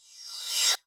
CYCdh_Kurz07-RevCrash01.wav